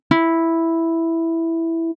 • 弦からボディに伝播する音